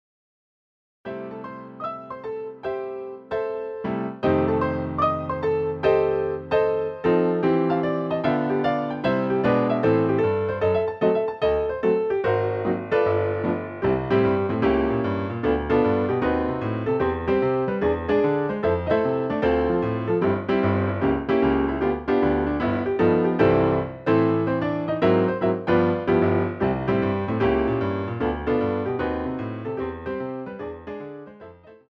CD quality digital audio Mp3 file recorded
using the stereo sampled sound of a Yamaha Grand Piano.